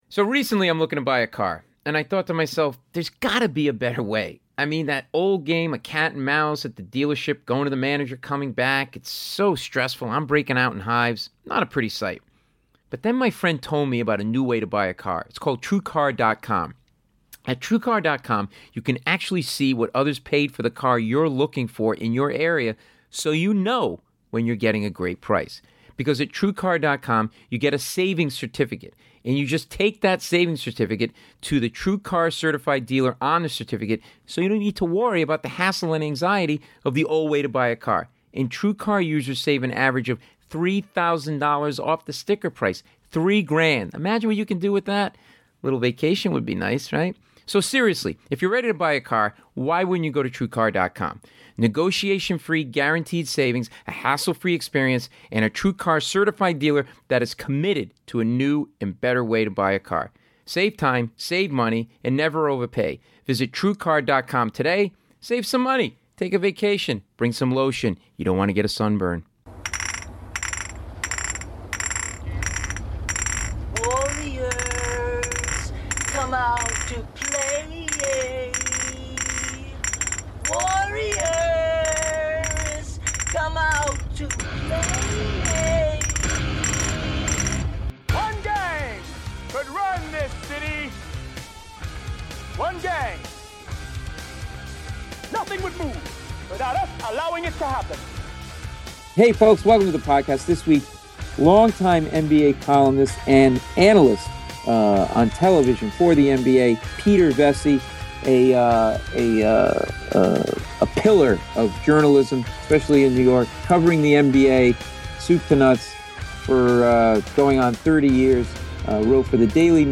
A great conversation about his life, how he rose to prominence in the NBA and what it was like covering and hanging with these NBA legends.